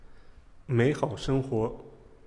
描述：一个人说住在中文里比较慢